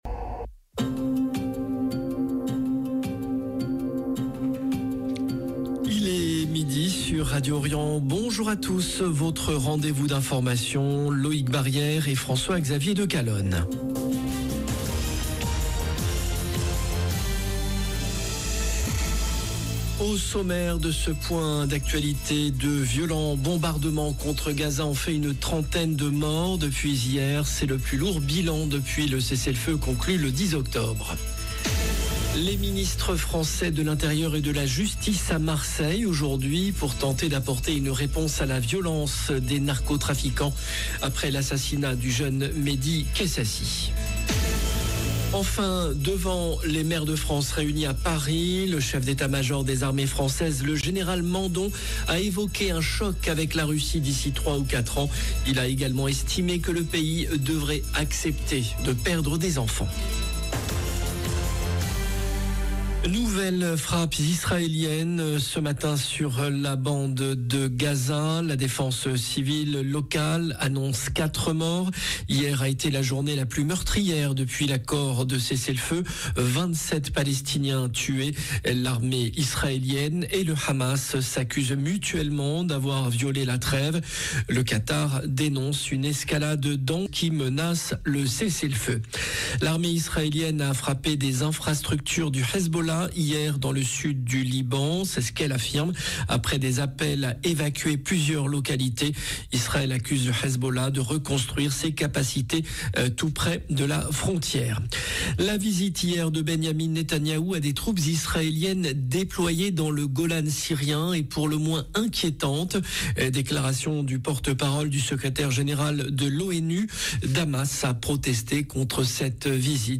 JOURNAL DE MIDI